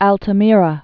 (ăltə-mîrə, ältä-mērä)